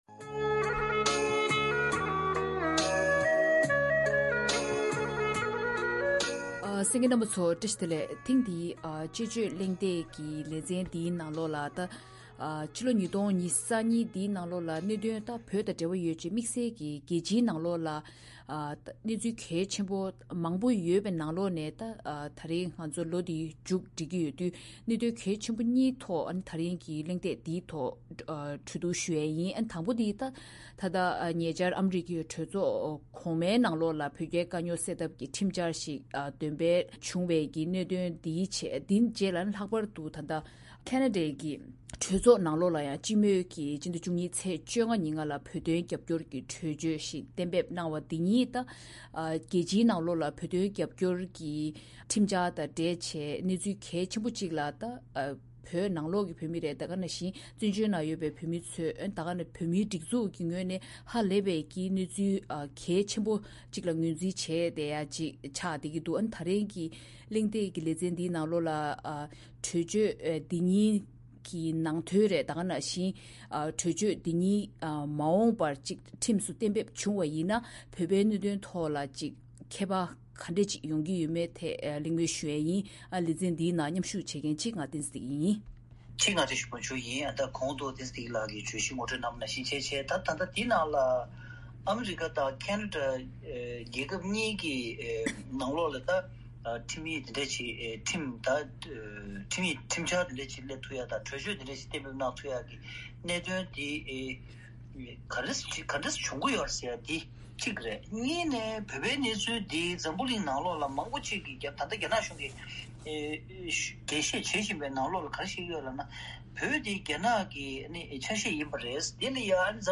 གླེང་མོལ་གནང་བའི་ལས་རིམ།